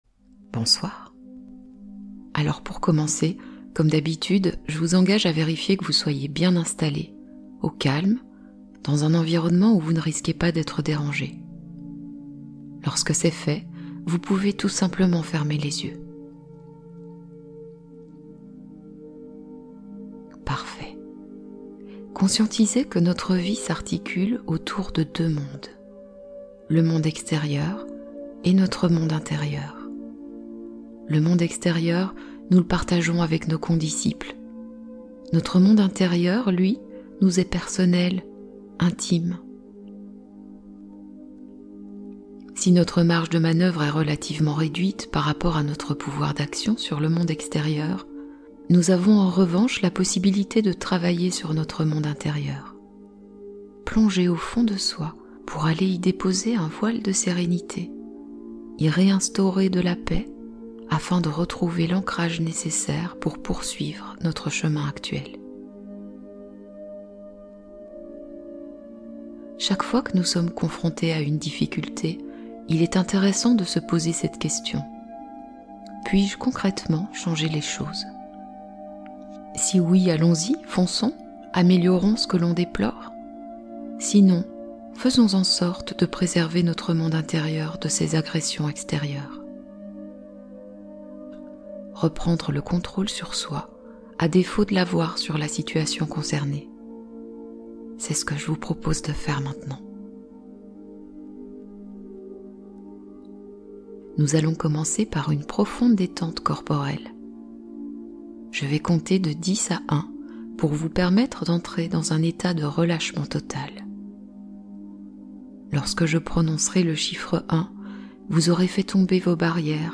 Hypnose inédite : sommeil naturel avec sons apaisants